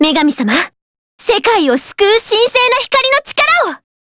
Maria_L-Vox_Skill6_jp.wav